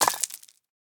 stonefail1.ogg